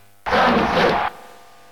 Samus Crowd Cheer in Dairantou Smash Brothers
Samus_Cheer_Japanese_SSB.ogg